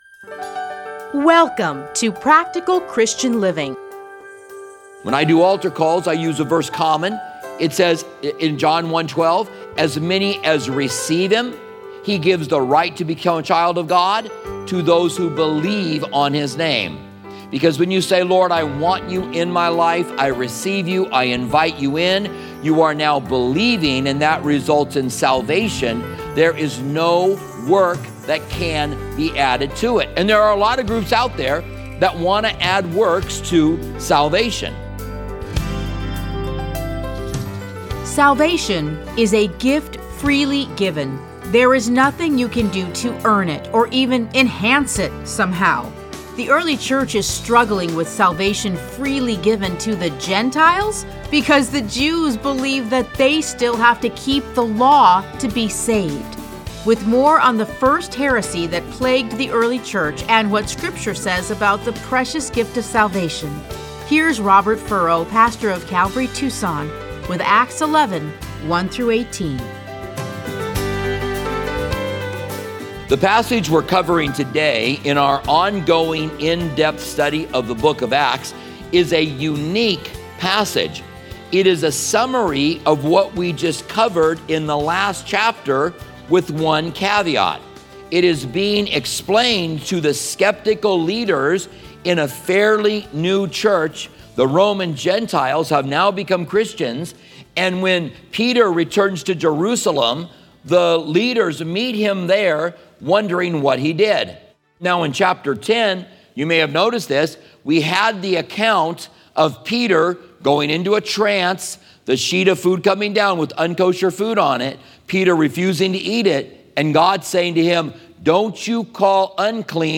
Listen to a teaching from Acts 11:1-18.